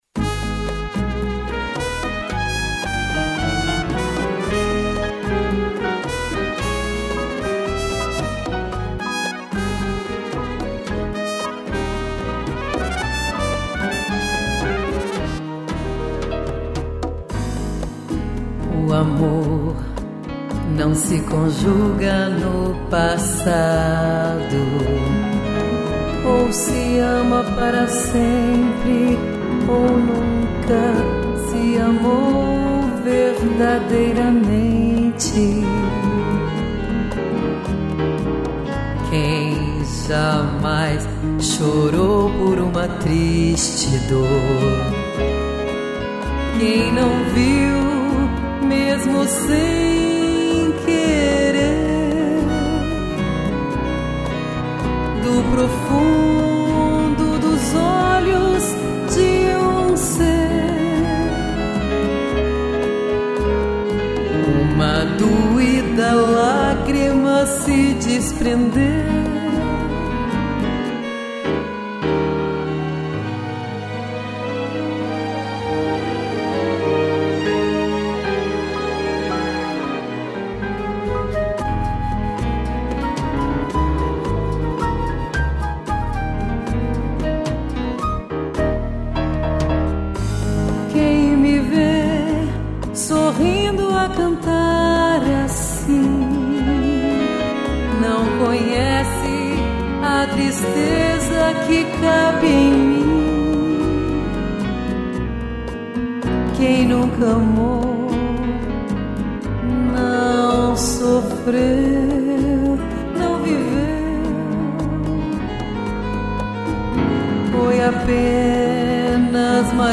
piano, cello e violino